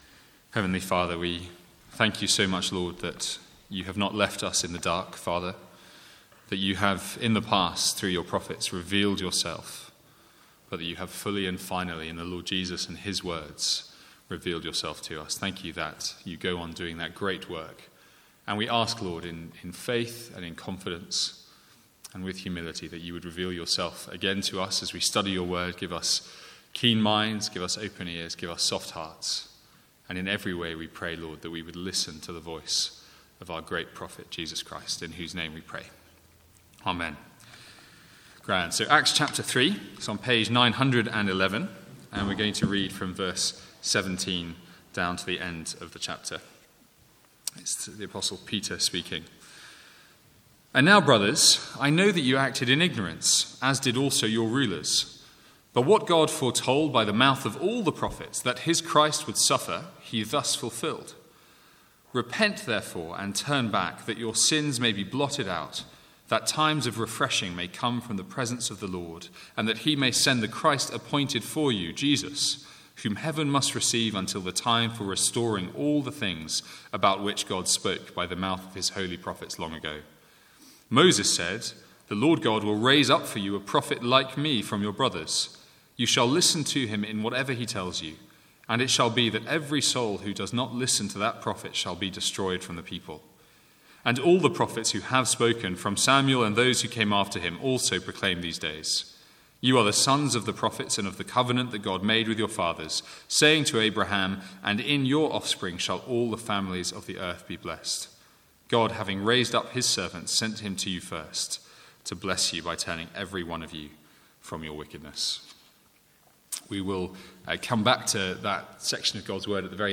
Sermons | St Andrews Free Church
From the Sunday evening series 'Jesus as Prophet, Priest and King'.